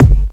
INSKICK20 -R.wav